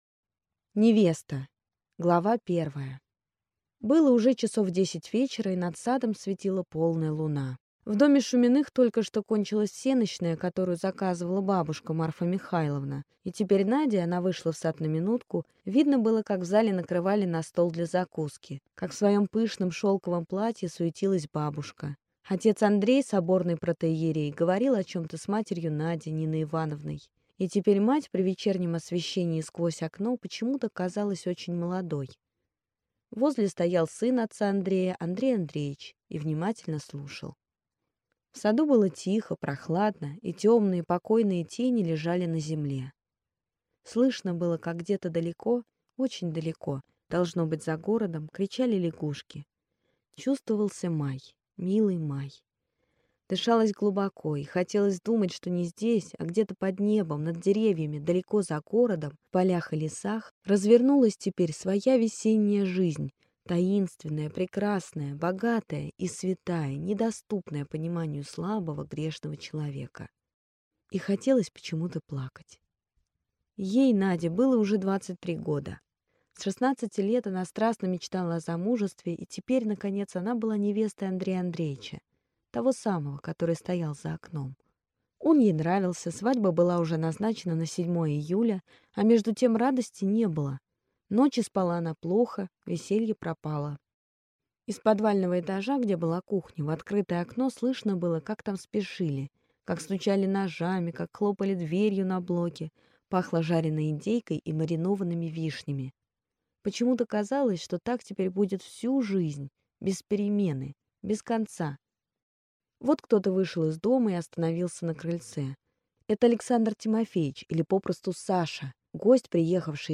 Аудиокнига Невеста | Библиотека аудиокниг